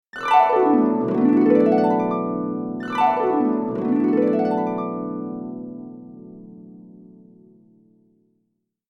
Download Harp sound effect for free.
Harp